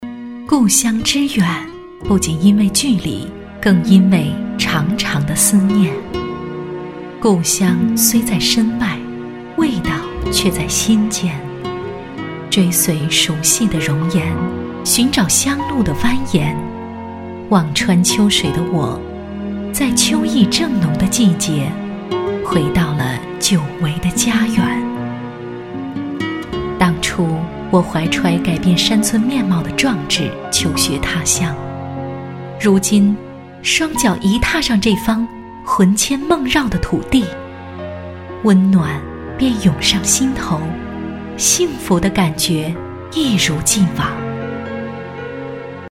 地方宣传片女20号（二龙屯
深情缓慢 城市形象
成熟质感女音，擅长宣传片，旁白讲述，记录片，专题题材。作品：二龙屯。